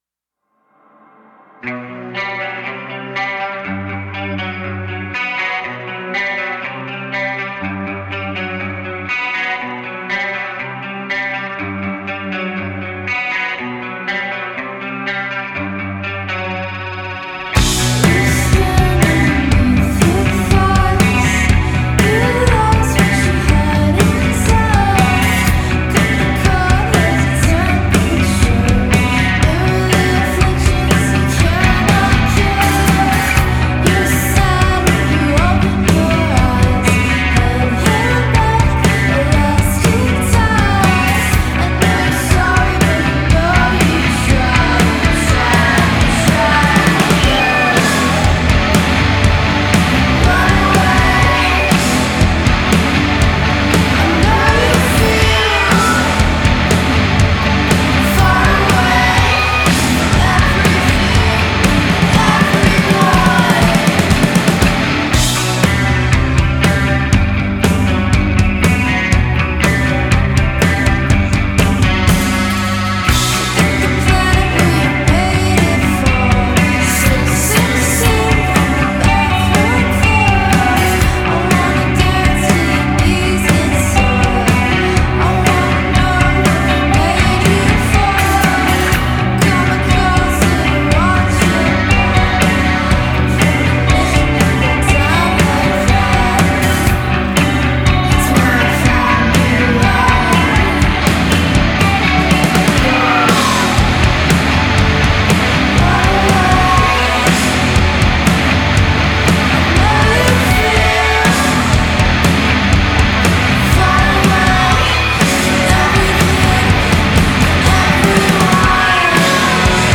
thoughts on "grungegaze"?